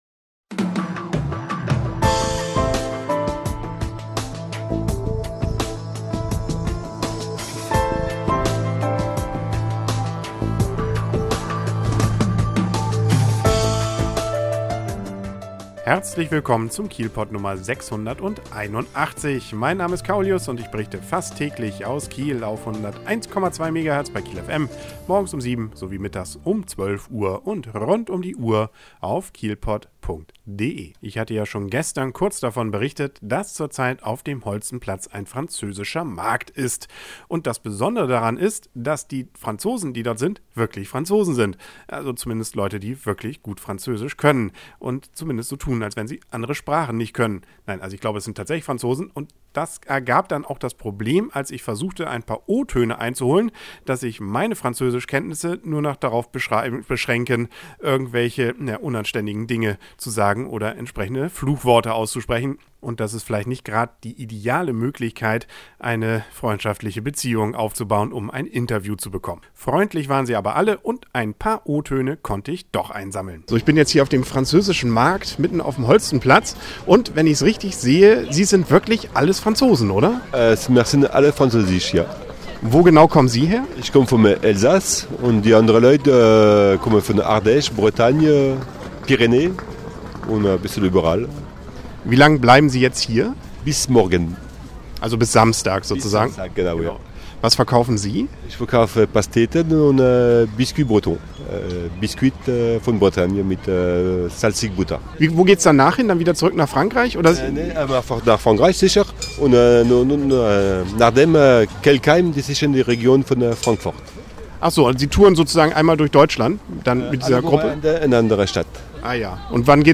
Ich war auf dem Französischen Markt auf dem Holstenplatz und habe mich mit einigen der Verkäufer und Kunden unterhalten.